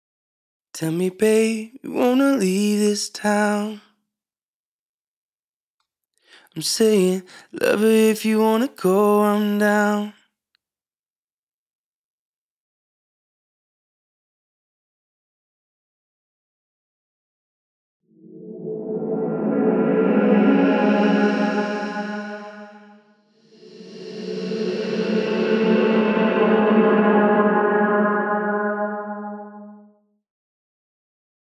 Creating Pads and Atmospheres
One interesting way to use your loop is to turn it into a pad.
Combine this with some volume and filter automation to create lush atmospheres.
Pads.wav